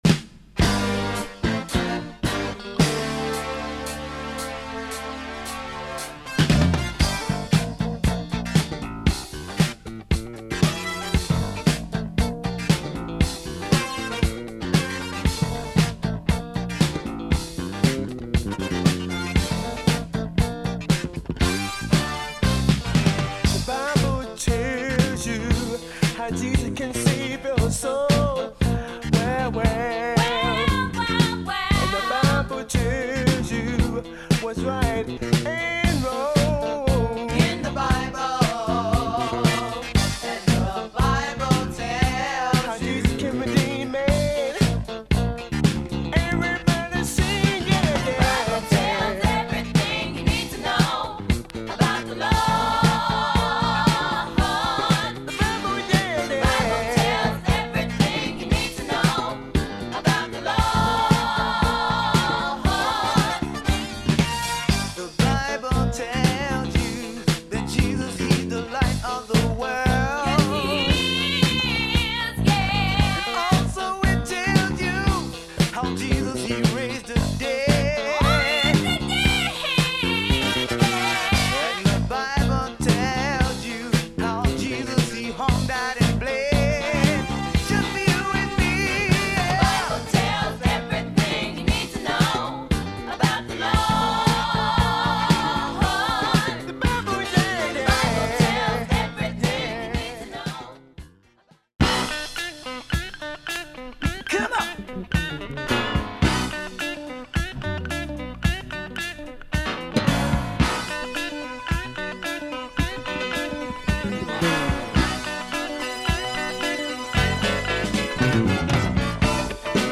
Killer gospel boogie soul I found in Detroit.